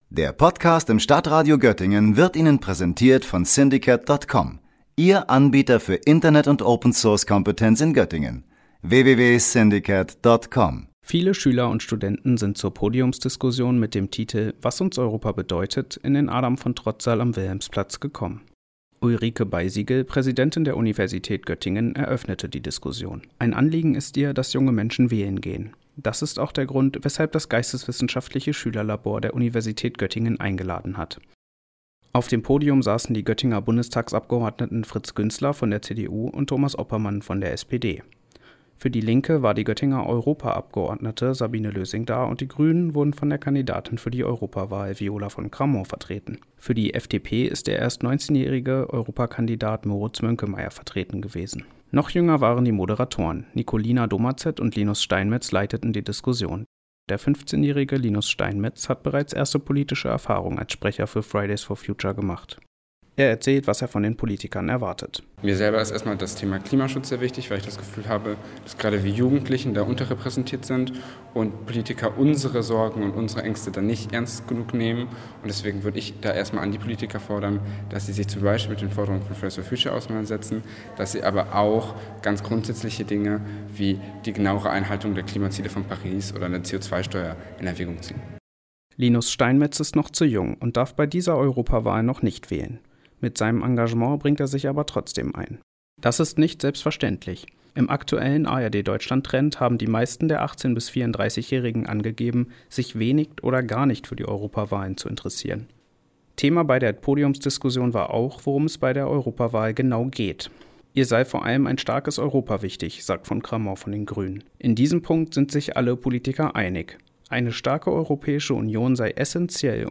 Im Saal herrschte eine angeregte Stimmung.
Zwischendurch brachten die anwesenden Zuhörer Fragen in die Runde ein.